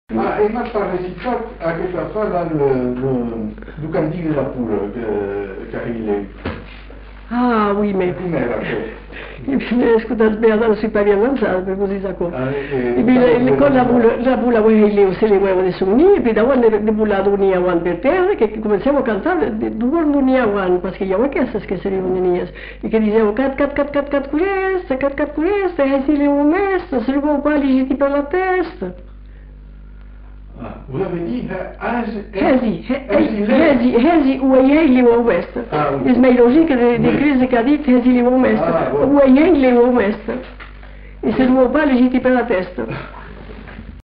Aire culturelle : Bazadais
Lieu : Lerm-et-Musset
Genre : forme brève
Type de voix : voix de femme
Production du son : récité
Classification : mimologisme